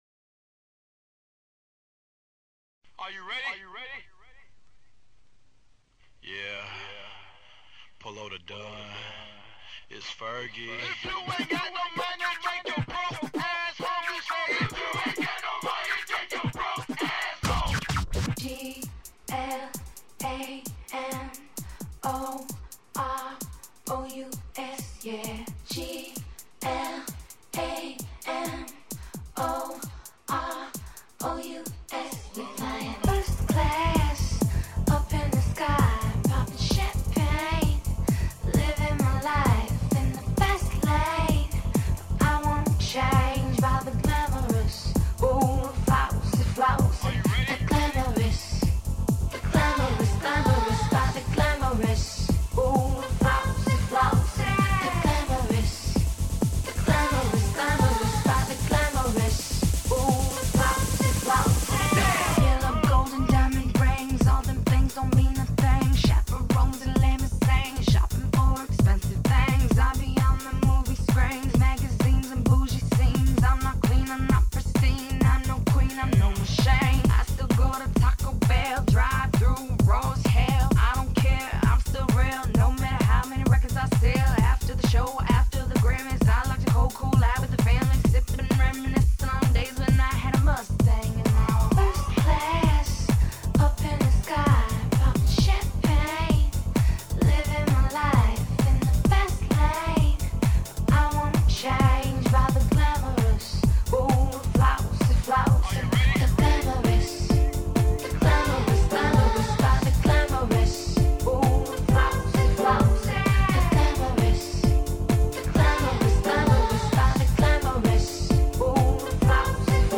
dance/electronic
its a pumping version
Club Anthems